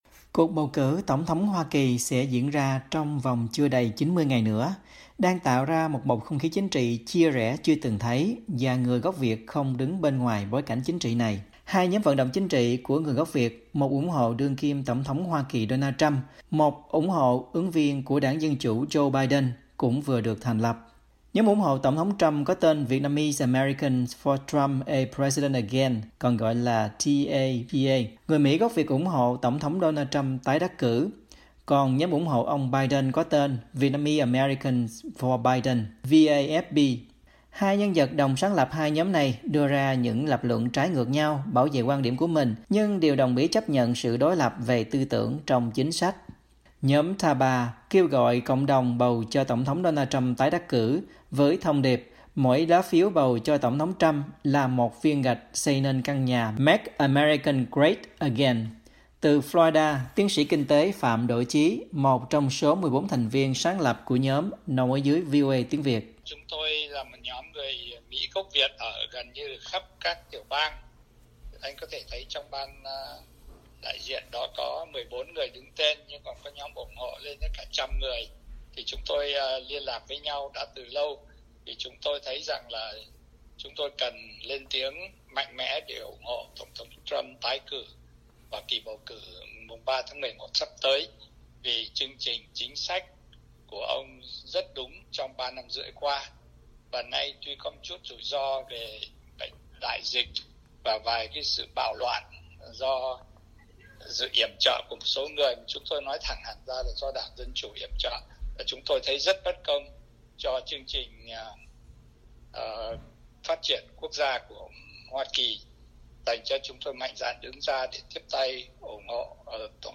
VOA phỏng vấn